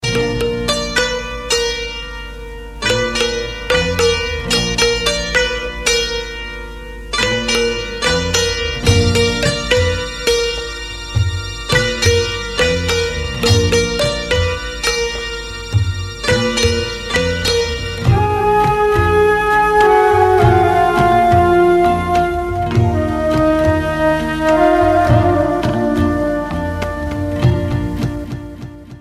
Bollywood slow